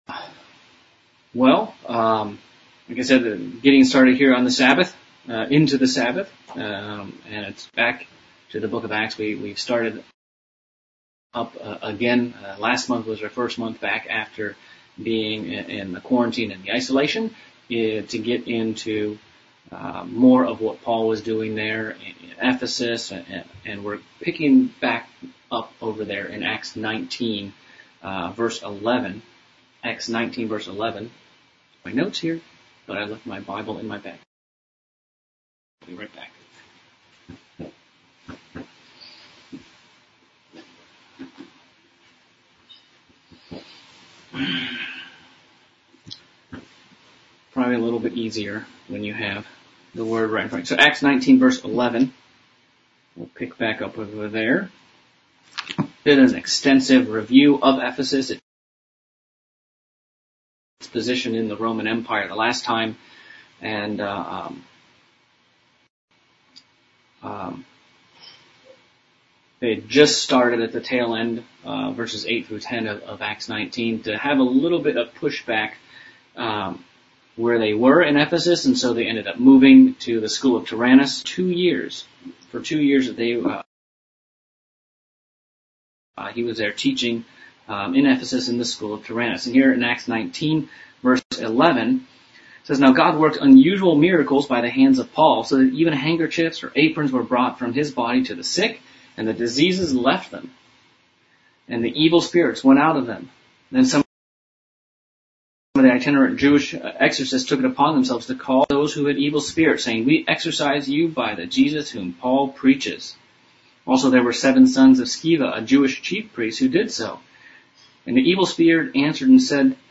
August Bible Study-Acts 19